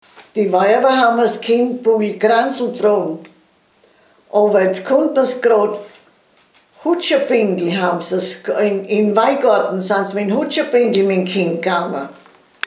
Mundart: Wuderscher Dialekt
Man hörte dabei eine gedehnte „ua“ Aussprache.
Unser Budaörser Heimatmuseum besitzt eine Sammlung von Tonaufnahmen in wunderbar gesprochenem Wuderscher Dialekt, den wir in einer Hörprobe präsentieren: